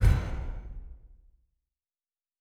Fantasy Interface Sounds
Special Click 24.wav